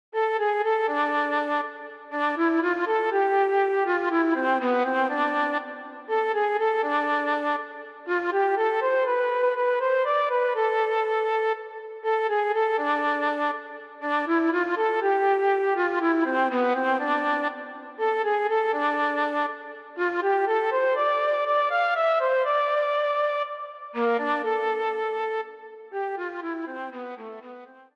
メロディは仮でフルートの音を当てていて、また、イントロではバイオリンを使用しています。
メロディ
luflen-melody-3.mp3